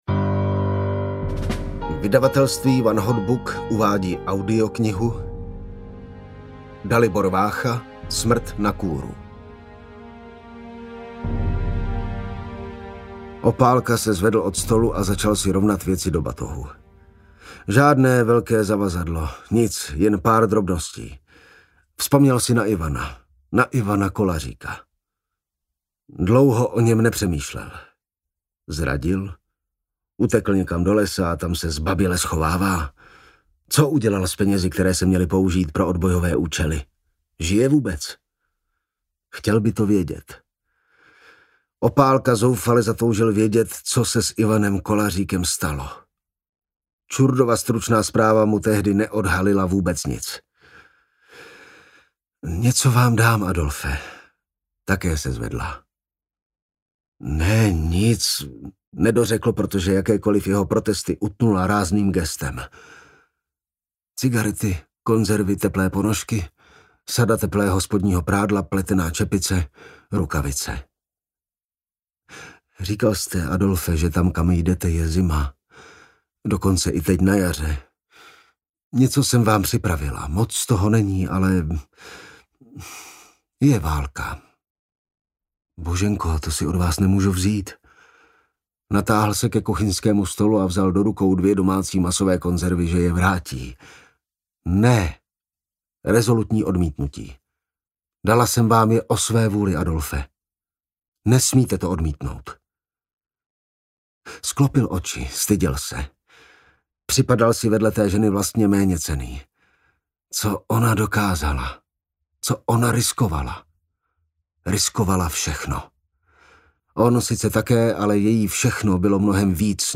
Smrt na kůru audiokniha
Ukázka z knihy